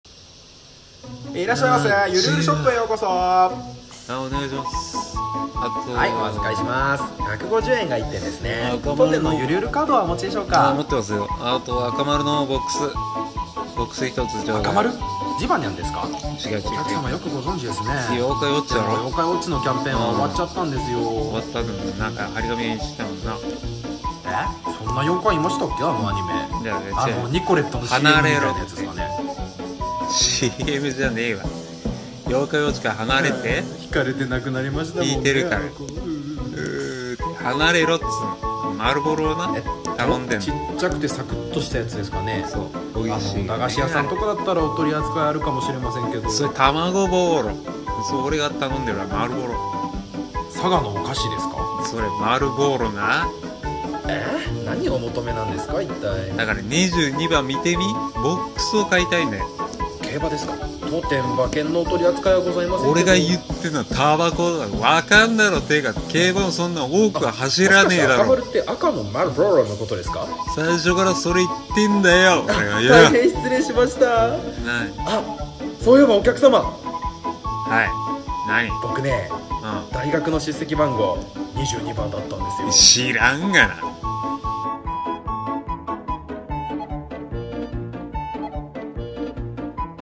緩声劇「赤マルが通じない店員」